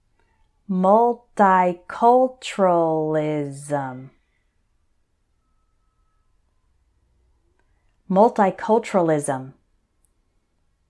So for these words I’ll say them once slowly and once normally, so you can repeat both times.
mul – ti – CUL – tur – al – is – m……… multiculturalism